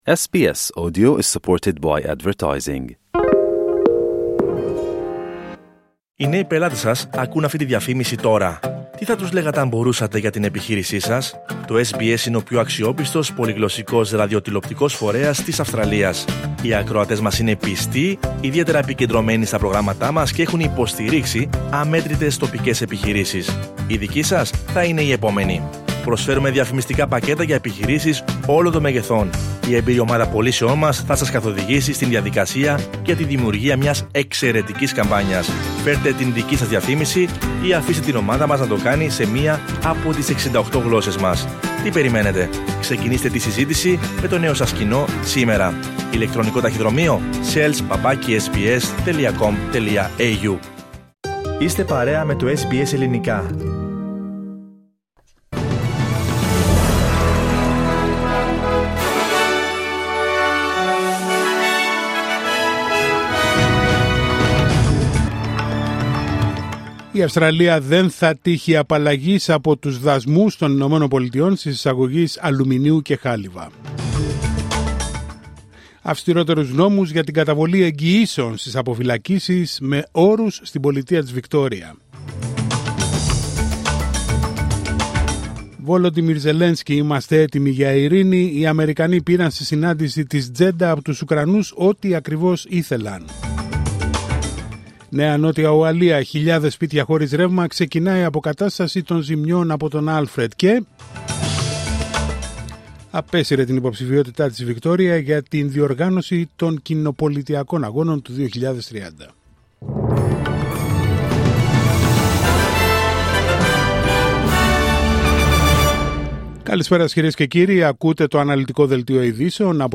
Δελτίο ειδήσεων Τετάρτη 12 Μαρτίου 2025